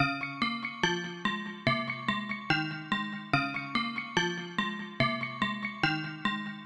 描述：在FlStudio制造
标签： 144 bpm Trap Loops Synth Loops 1.12 MB wav Key : C